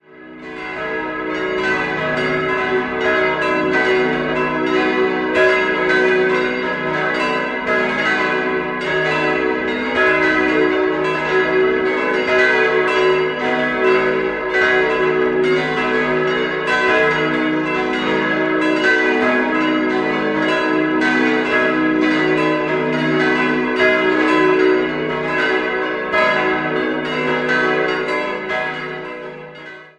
Altäre und Kanzel stammen aus der Zeit des Rokoko. 6-stimmiges Geläut: dis'-fis'-gis'-h'-cis''-fis'' Die drei großen Glocken wurden 1956 von der Gießerei Otto in Bremen-Hemelingen gegossen, Glocke 4 im Jahr 1653 in Fladungen und die beiden kleinen entstanden 1664/65 in Würzburg.